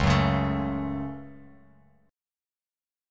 C#sus4#5 chord